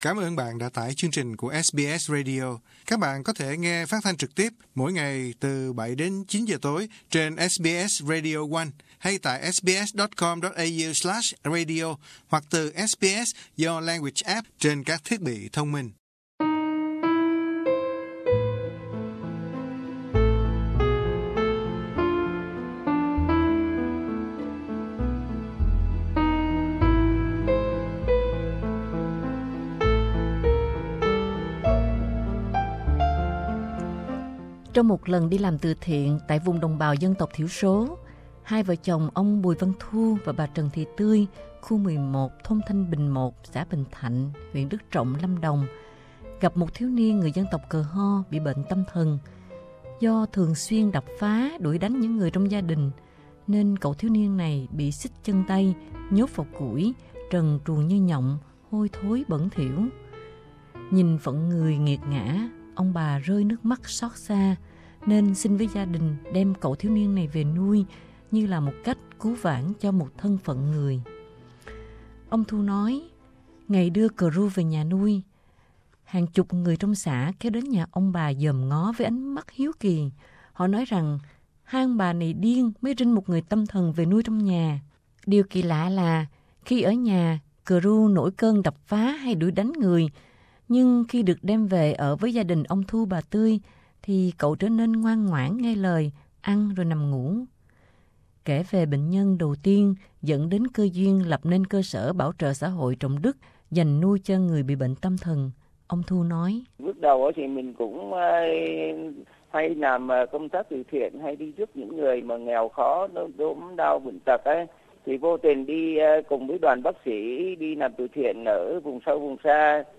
cuộc trò chuyện